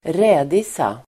Ladda ner uttalet
rädisa substantiv, radish Uttal: [²r'äd:isa] Böjningar: rädisan, rädisor Definition: den röda rotgrönsaken Raphanus sativus radish substantiv, rädisa Förklaring: den röda rotgrönsaken Raphanus sativus